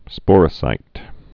(spôrə-sīt)